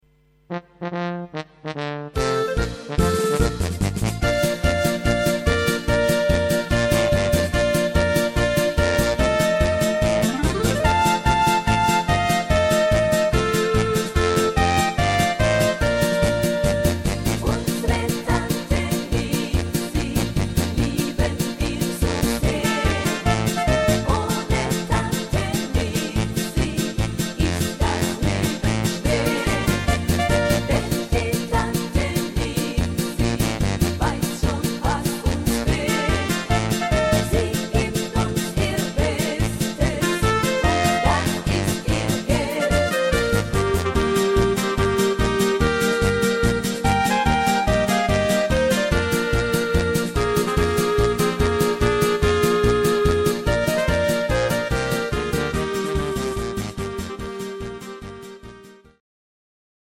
Rhythmus  Polka
Art  Volkstümlich, Deutsch, Fasching und Stimmung